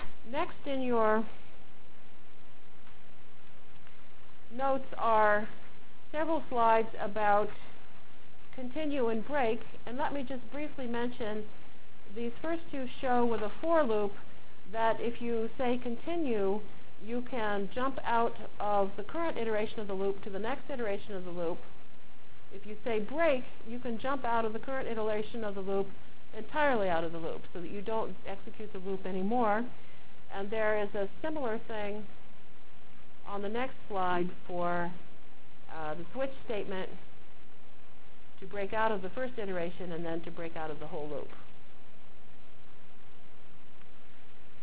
From Jan 22 Delivered Lecture for Course CPS616